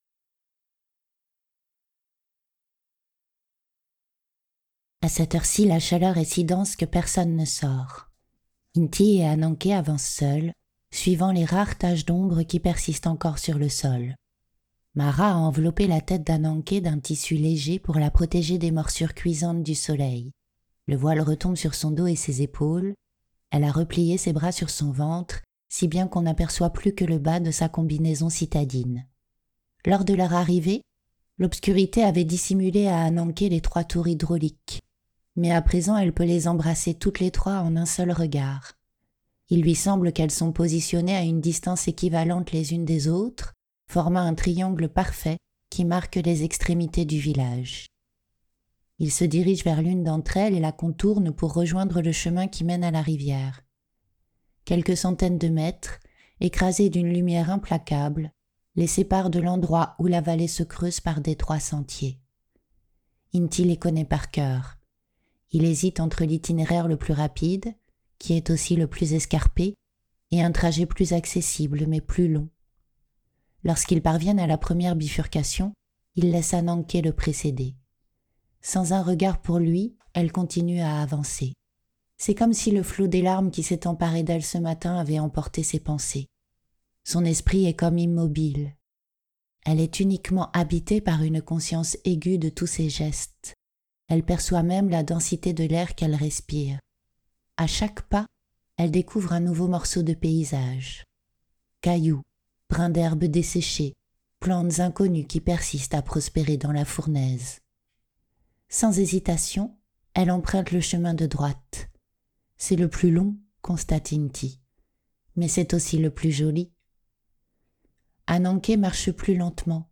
Les extraits ci-dessous sont issus de mon propre texte, Les yeux dans Ajna, et explorent d’autres registres de narration, entre conte philosophique et atmosphère symbolique.
Scène dialoguée